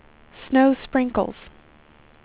WindowsXP / enduser / speech / tts / prompts / voices / sw / pcm8k / weather_86.wav